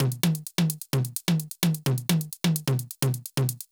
CLF Beat - Mix 18.wav